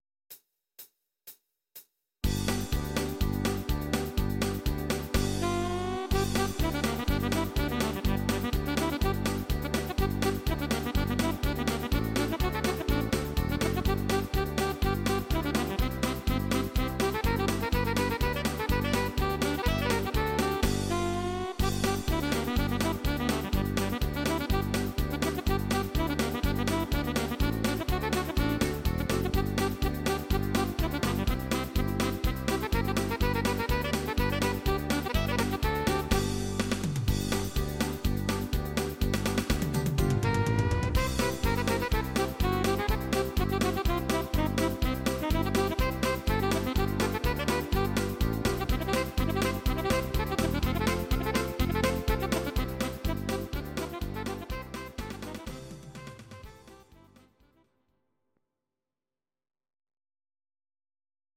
These are MP3 versions of our MIDI file catalogue.
Please note: no vocals and no karaoke included.
Saxophon